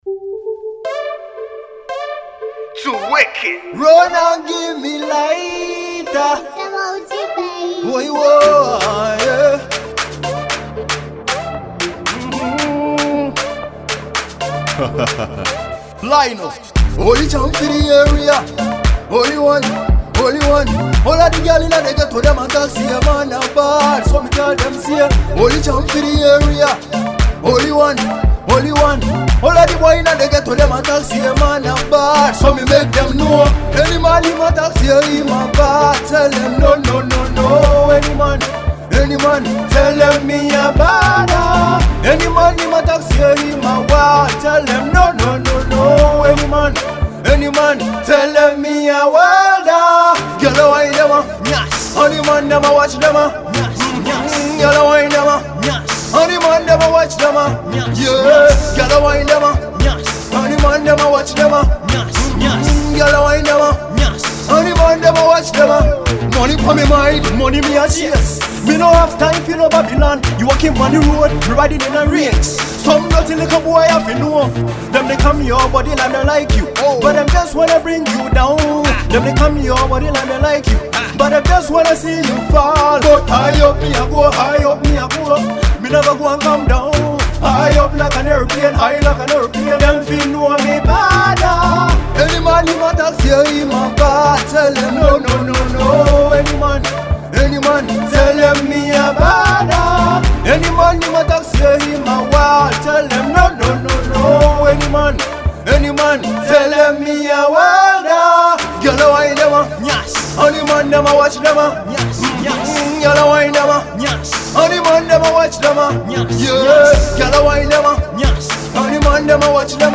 Afrobeats musician